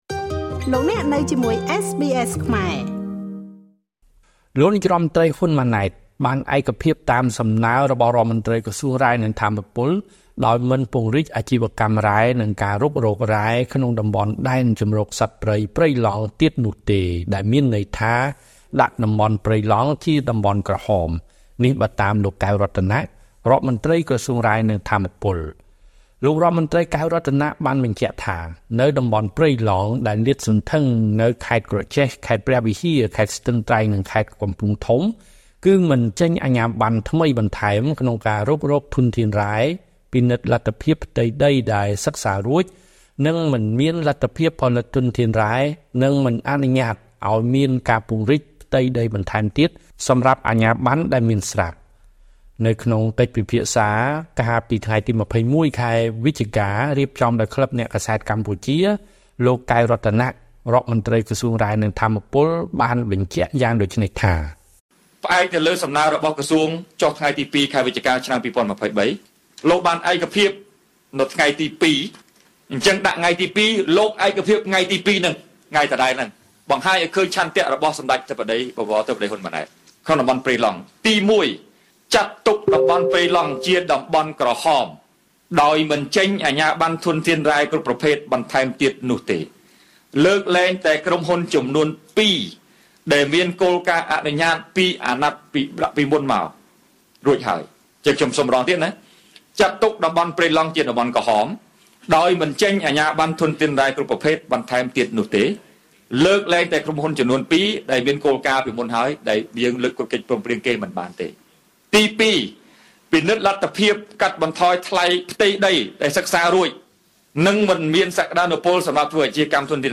នៅក្នុងកិច្ចពិភាក្សា កាលពីថ្ងៃទី២១ ខែវិច្ឆិកា ឆ្នាំ២០២៣ រៀបចំដោយក្លិបអ្នកកាសែតកម្ពុជា លោក កែវ រតនៈ រដ្ឋមន្ត្រីក្រសួងរ៉ែ និងថាមពល បានបញ្ជាក់យ៉ាងដូច្នេះថា៖